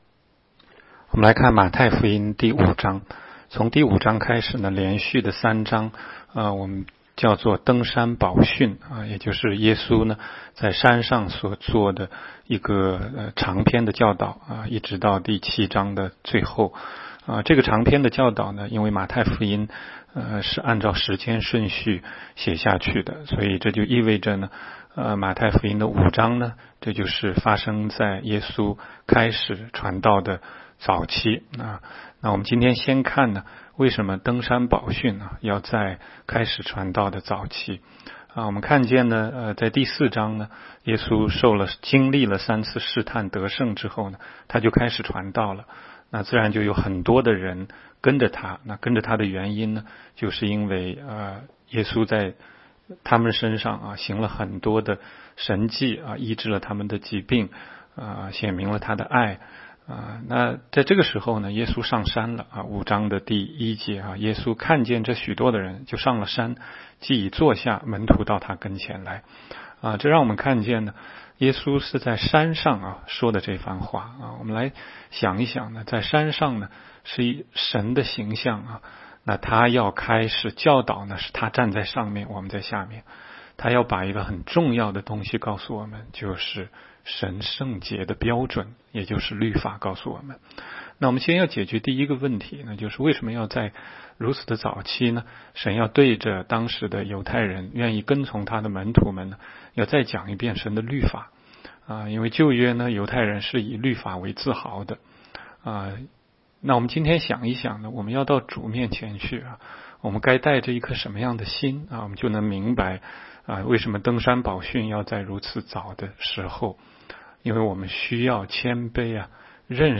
16街讲道录音 - 每日读经-《马太福音》5章